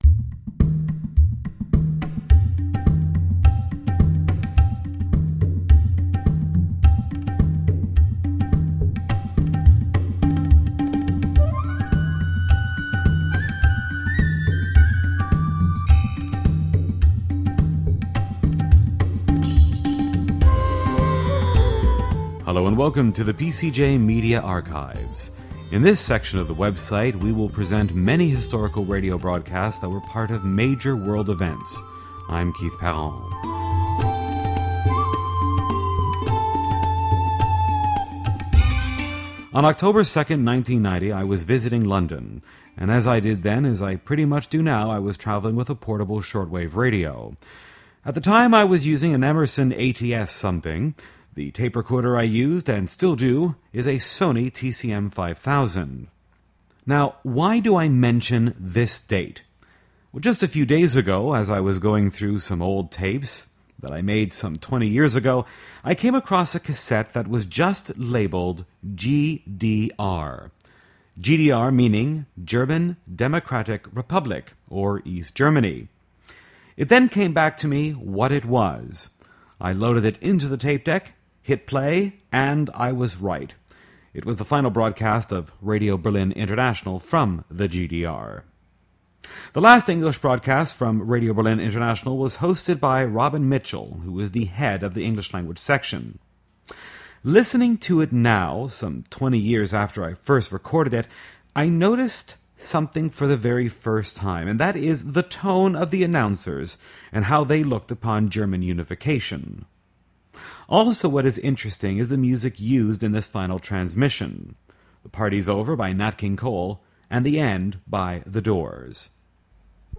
The broadcast takes place one day prior to Germany’s reunification on Oct 2, 1990. This time machine MP3 recording is filled with clichés, bitter employees and some ironic/dry humor.
3101-1-Final_English_broadcast_of_Radio_Berlin_International.mp3